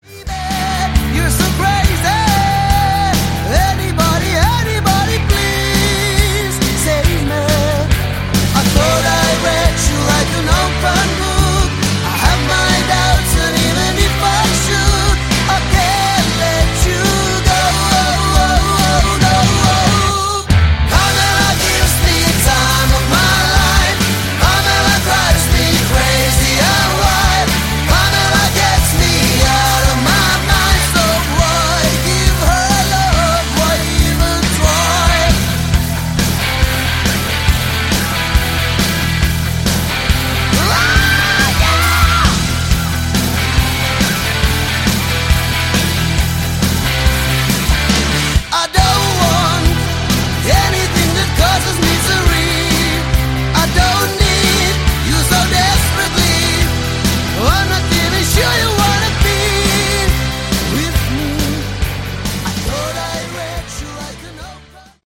Category: Sleaze Glam
vocals
bass
lead guitar
rhythm guitar
drums